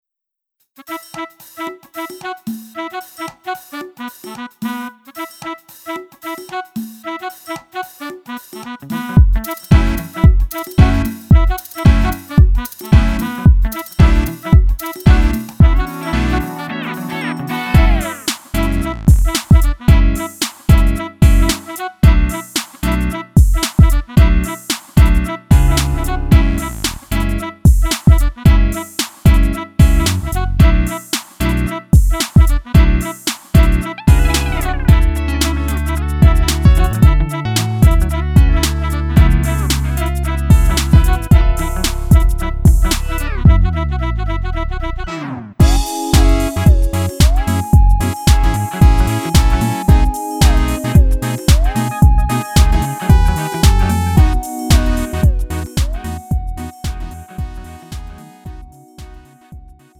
음정 -1키 3:57
장르 가요 구분 Lite MR